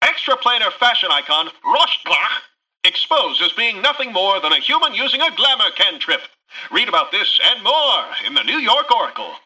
Newscaster_headline_10.mp3